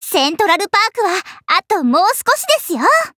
文件 文件历史 文件用途 全域文件用途 Ja_Fifi_amb_01.ogg （Ogg Vorbis声音文件，长度3.2秒，106 kbps，文件大小：41 KB） 源地址:游戏语音 文件历史 点击某个日期/时间查看对应时刻的文件。 日期/时间 缩略图 大小 用户 备注 当前 2018年5月25日 (五) 02:11 3.2秒 （41 KB） 地下城与勇士  （ 留言 | 贡献 ） 分类:祈求者比比 分类:地下城与勇士 源地址:游戏语音 您不可以覆盖此文件。